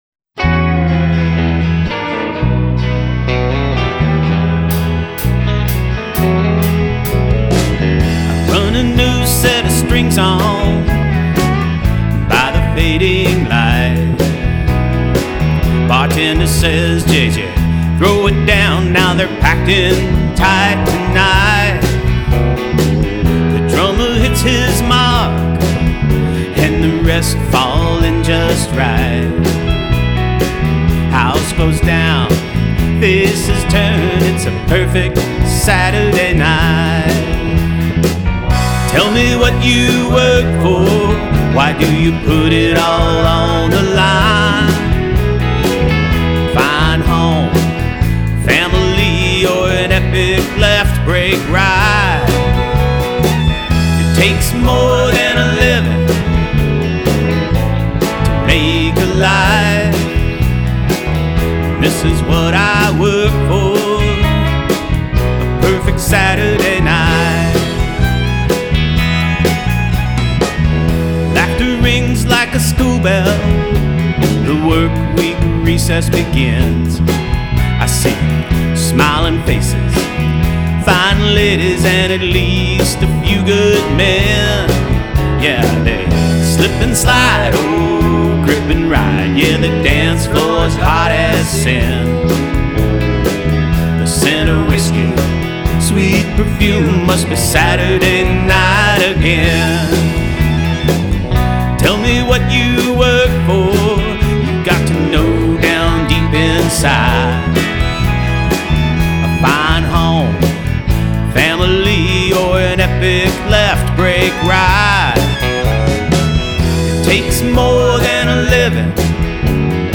vocals, Telecaster guitar